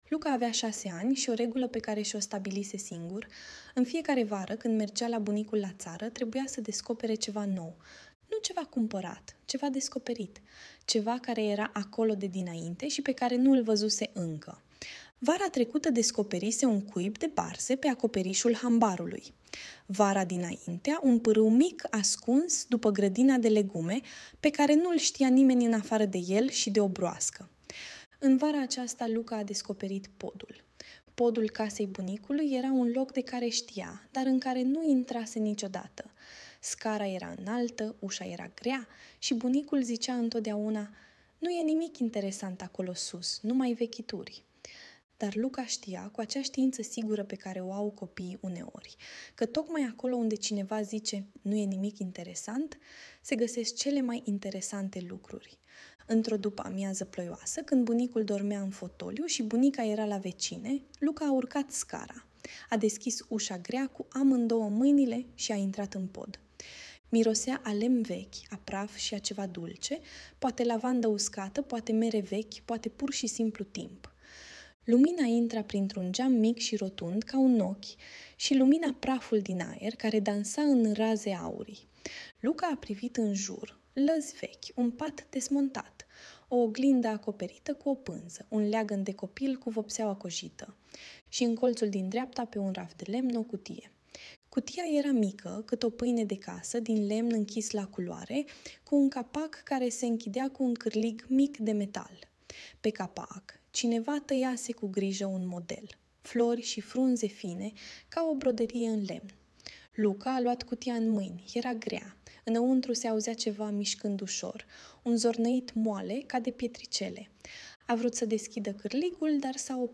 Audiobook Bunicul și cutia de lemn